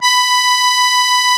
MUSETTE 1.16.wav